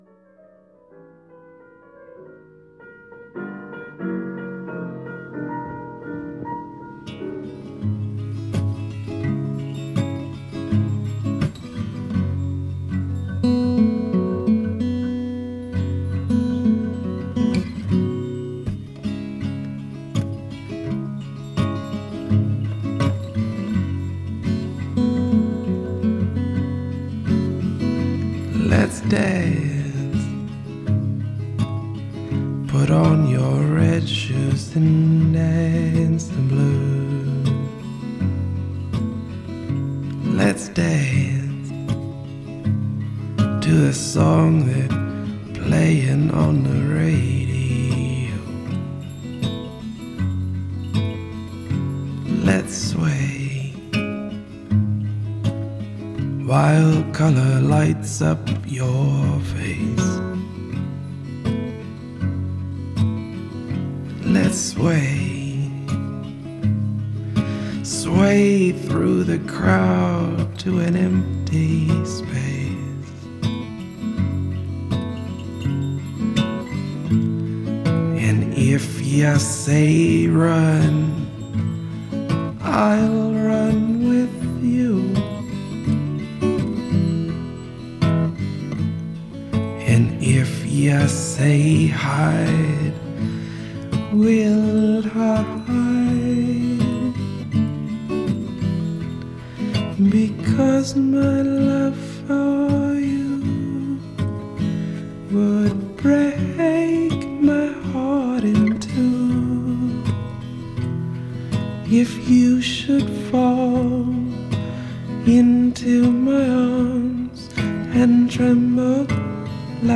Folk Jazz Rock